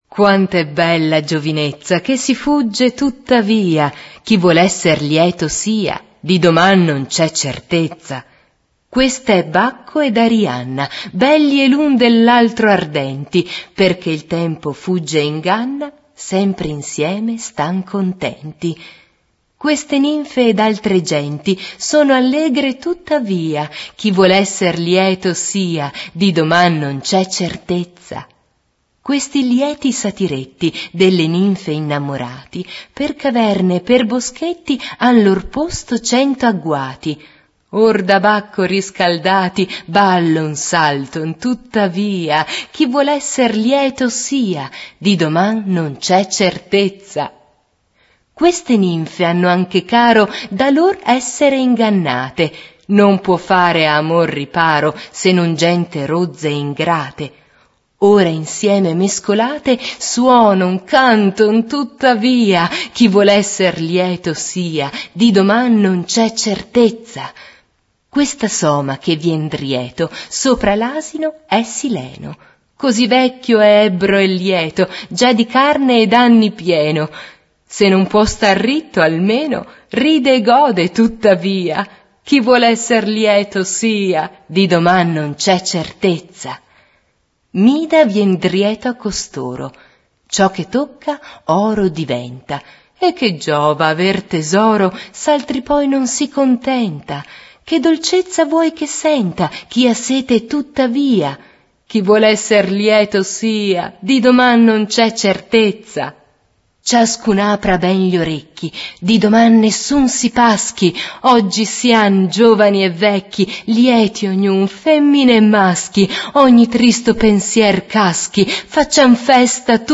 recitazione